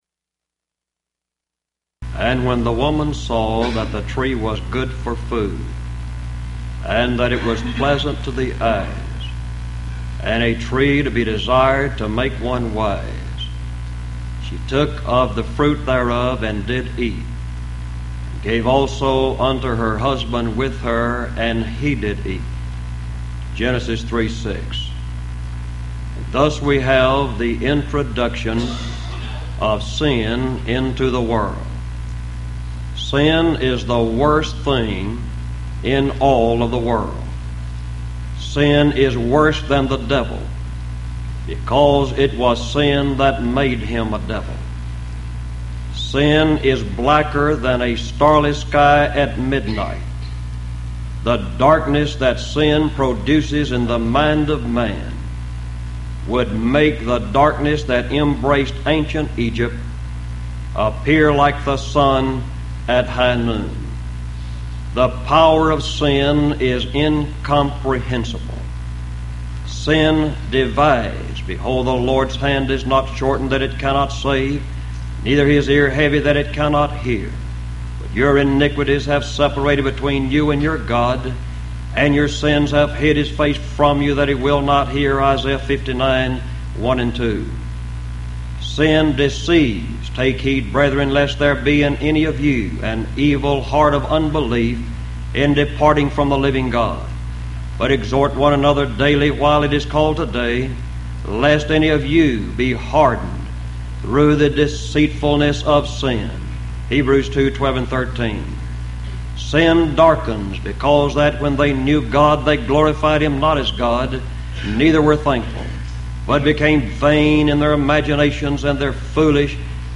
Event: 1997 HCB Lectures
lecture